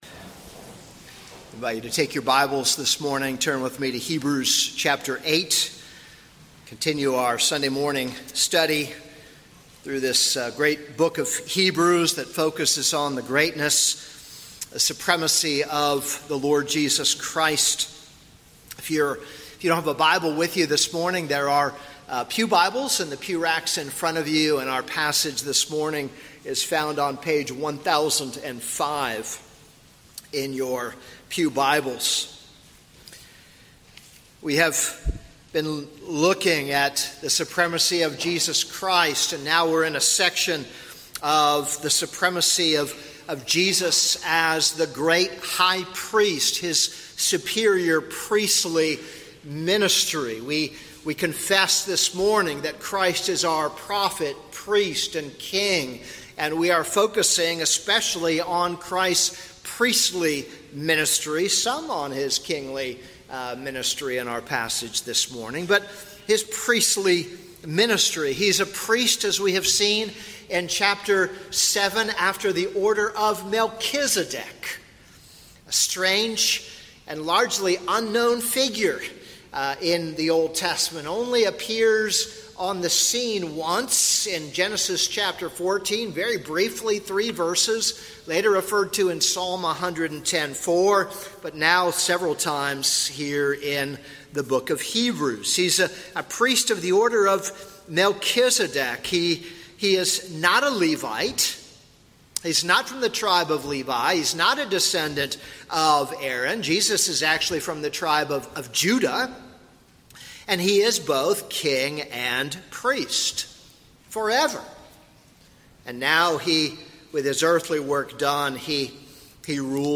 This is a sermon on Hebrews 8:1-6.